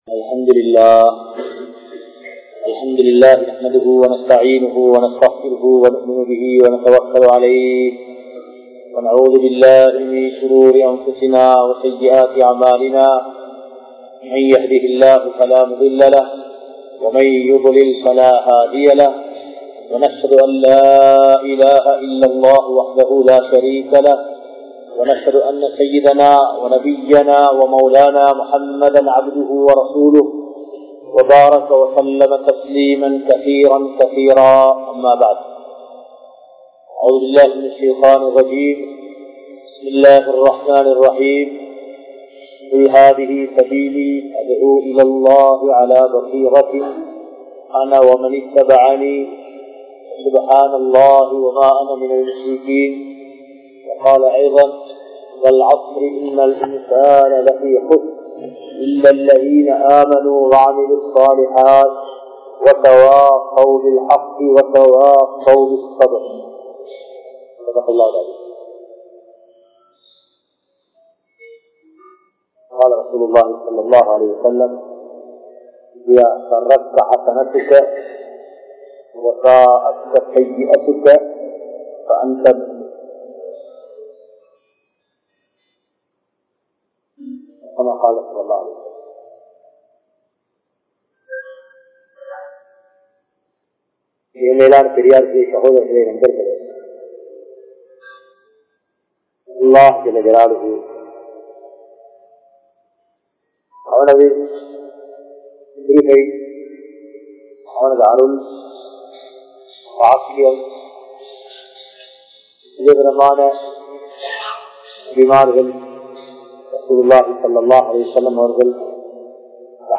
Ullaththitkaana Ulaippu (உள்ளத்திற்கான உழைப்பு) | Audio Bayans | All Ceylon Muslim Youth Community | Addalaichenai
Colombo, GrandPass Markaz